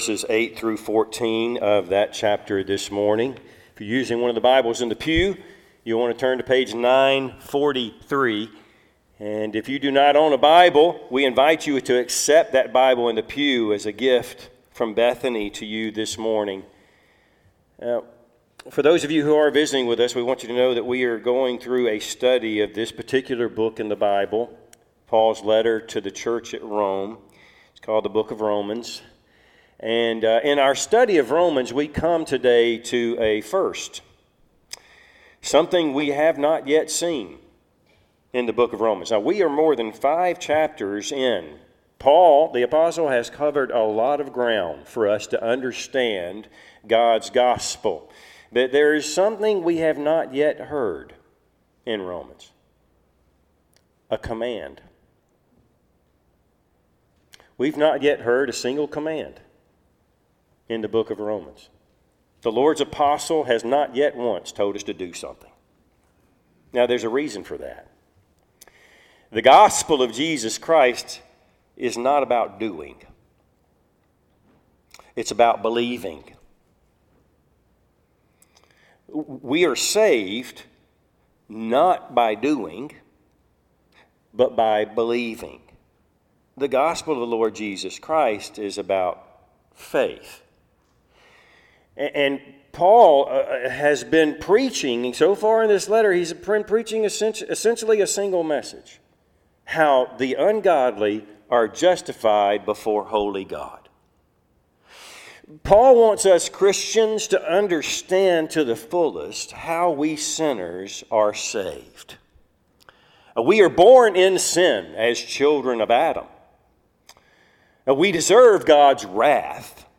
Service Type: Sunday AM Topics: Christian living , justification , Sanctification